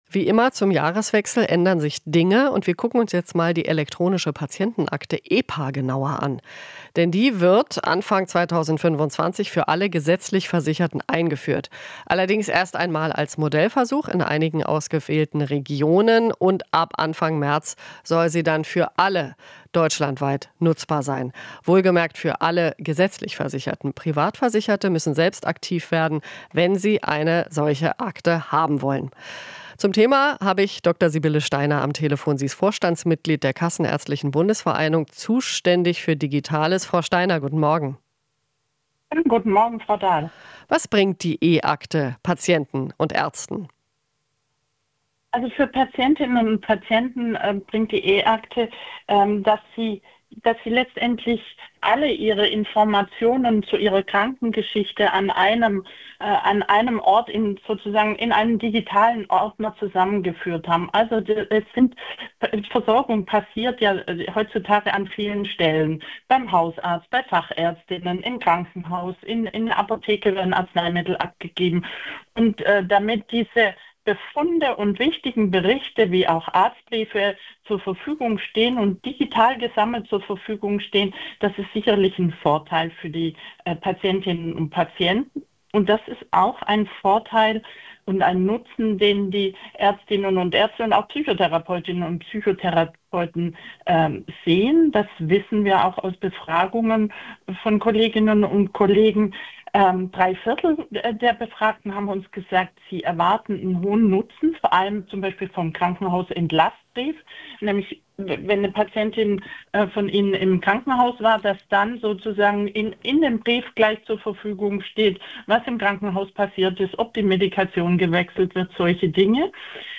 Interview - Elektronische Patientenakte wird getestet